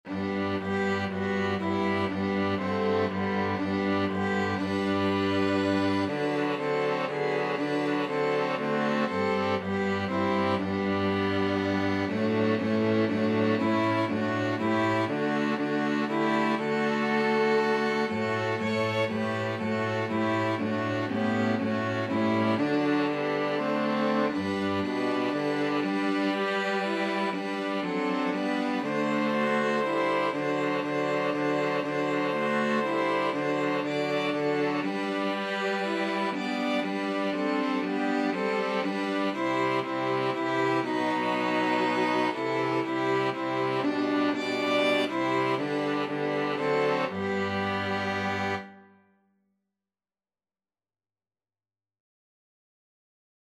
Christian
Violin 1Violin 2ViolaCello
6/4 (View more 6/4 Music)
Classical (View more Classical String Quartet Music)